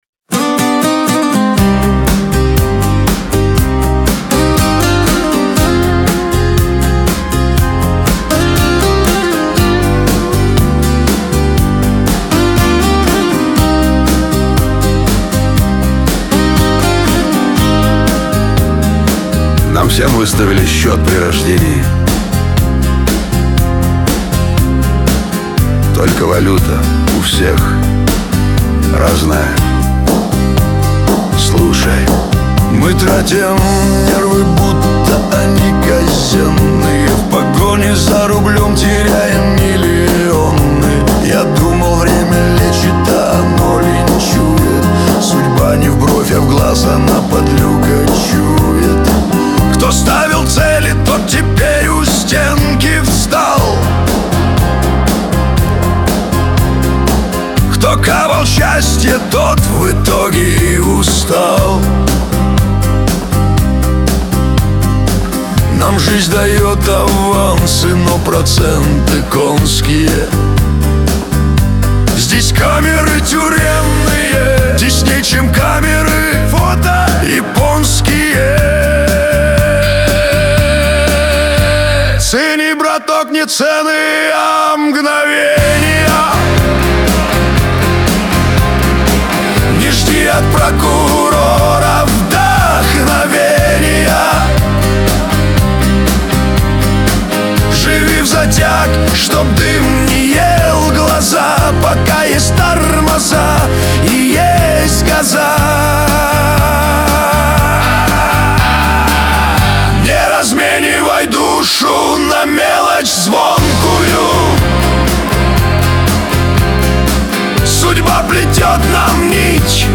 грусть
Шансон
Лирика